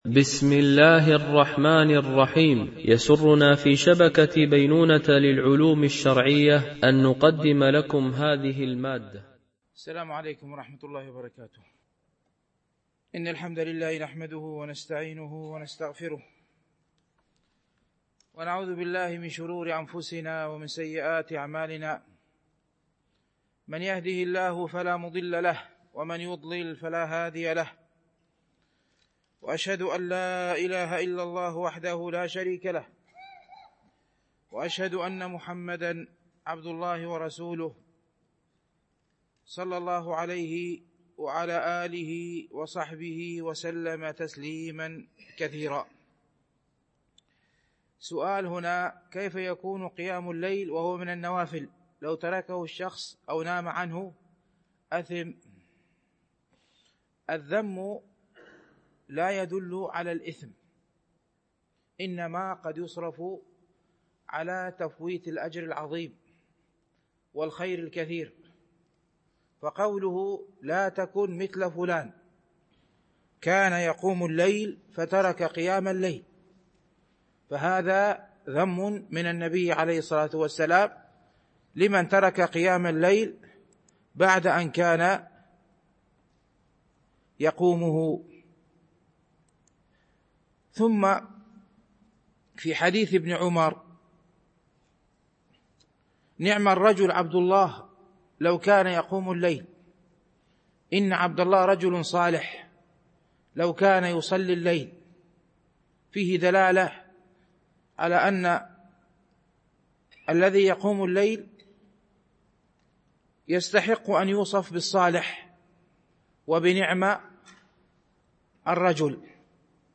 شرح رياض الصالحين – الدرس 303 ( الحديث 1174 - 1177 )